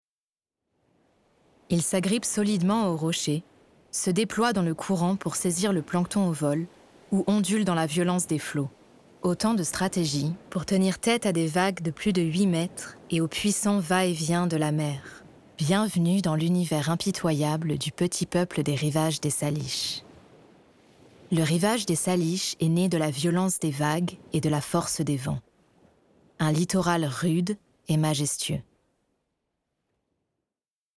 Démo voix - documentaire
8 - 50 ans - Mezzo-soprano